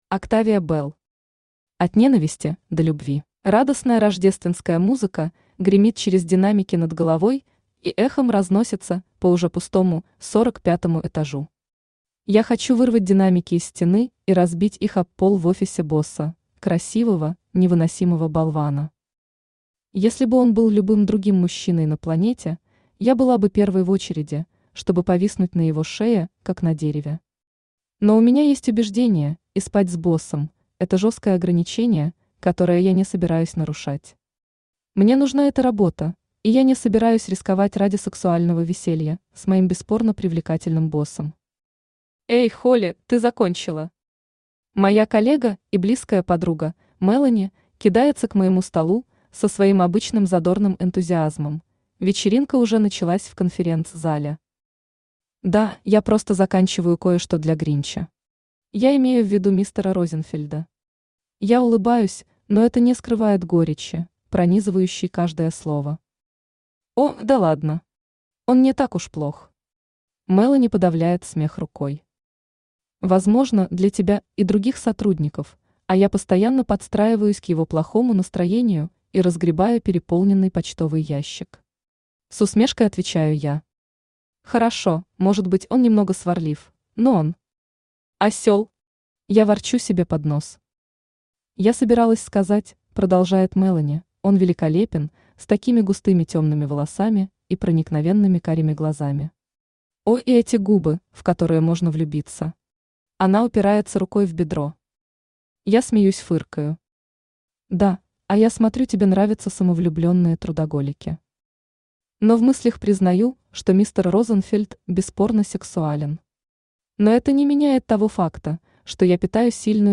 Аудиокнига От ненависти до любви | Библиотека аудиокниг
Aудиокнига От ненависти до любви Автор Октавия Белл Читает аудиокнигу Авточтец ЛитРес.